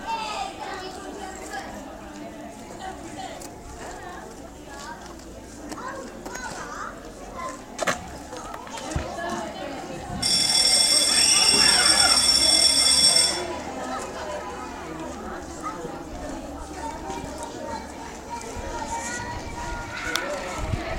school bell goes